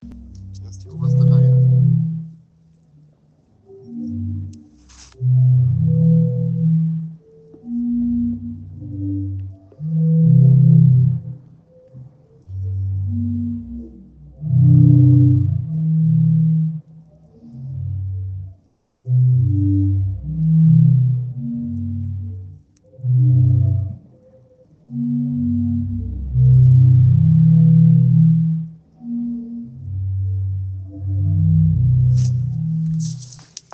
Die Stufen der Promenade wurden mit Hohlräumen gebaut, sodass eine sogenannte Meeresorgel entsteht. Die Wellen des Meeres erzeugen so einen Klang, der an eine Orgel bzw Wahlgesänge erinnerte.